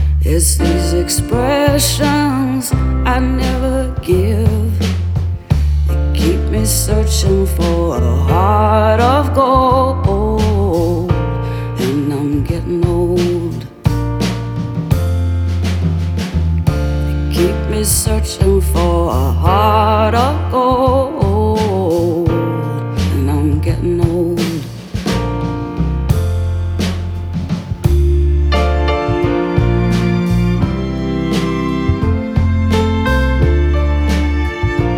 Alternative Indie Pop
Жанр: Поп музыка / Альтернатива